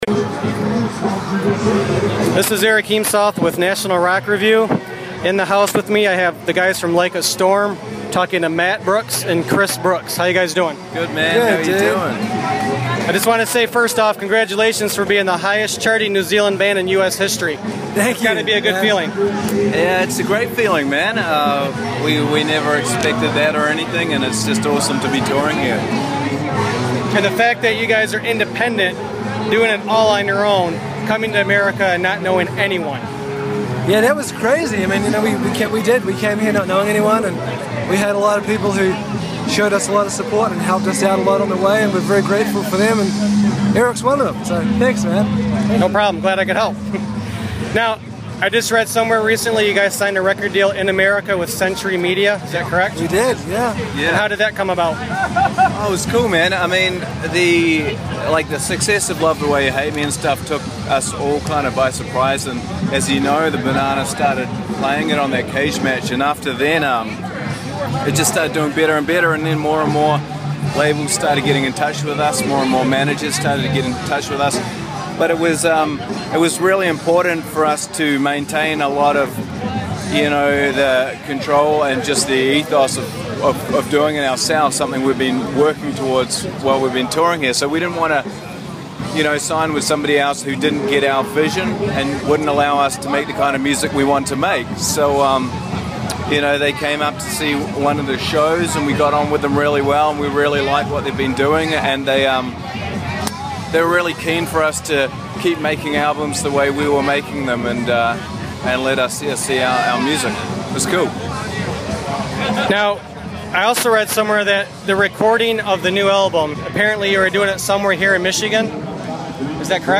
Like A Storm at Dirt Fest + Interview - National Rock Review